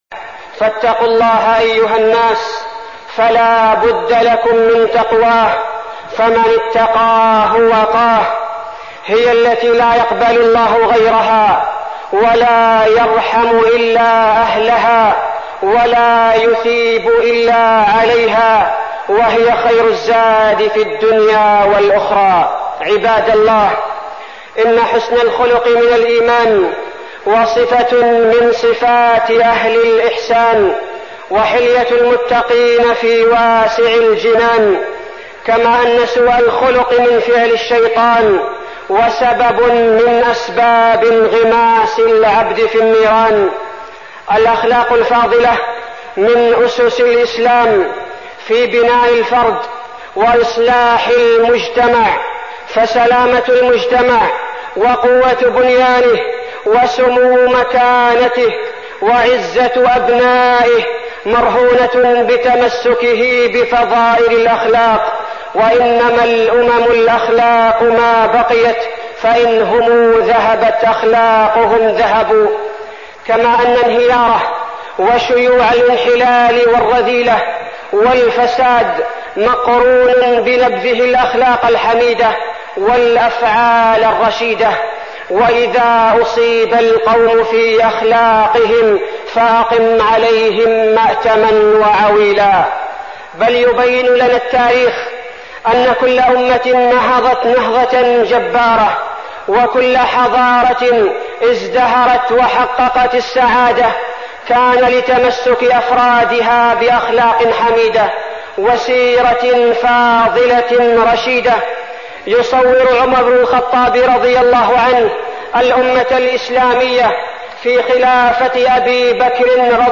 تاريخ النشر ٢١ رجب ١٤١٥ هـ المكان: المسجد النبوي الشيخ: فضيلة الشيخ عبدالباري الثبيتي فضيلة الشيخ عبدالباري الثبيتي حسن الخلق